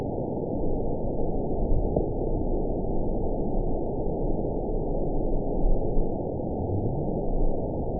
event 922698 date 03/12/25 time 03:56:55 GMT (1 month, 3 weeks ago) score 9.06 location TSS-AB10 detected by nrw target species NRW annotations +NRW Spectrogram: Frequency (kHz) vs. Time (s) audio not available .wav